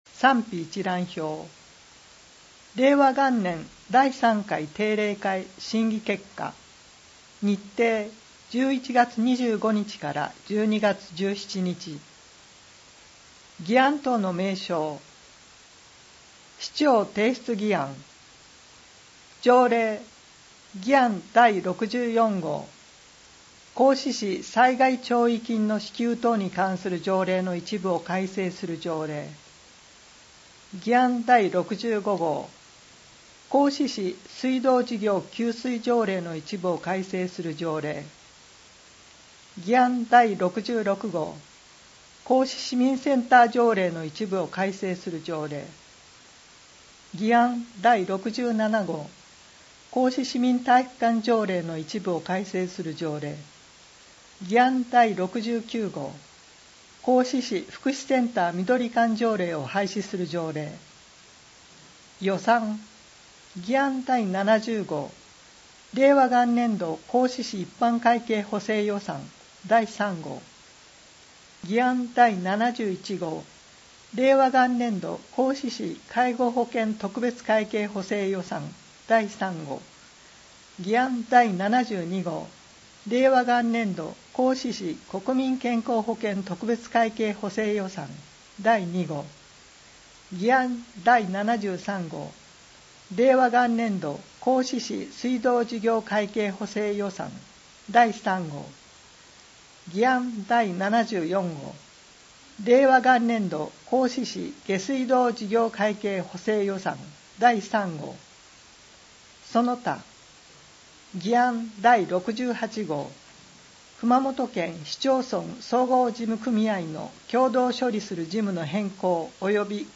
音声訳版 議会だより第56号